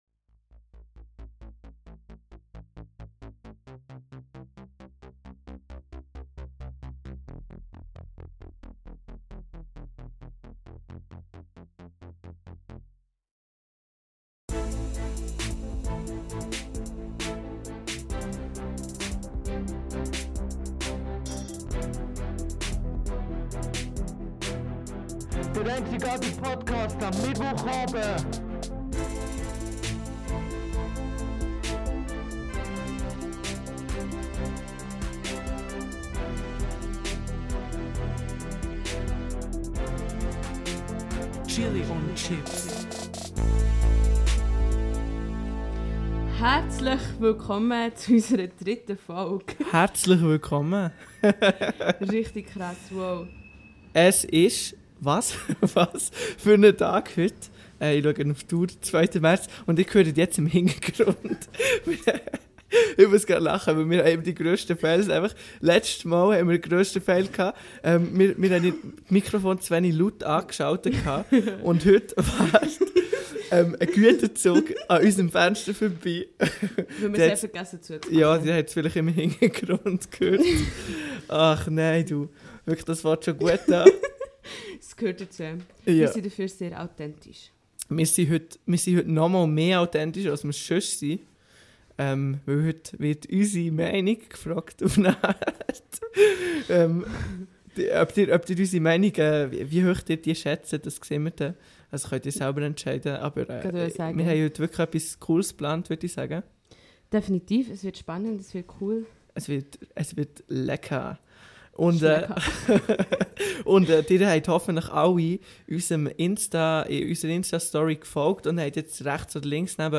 Der Live-Podcast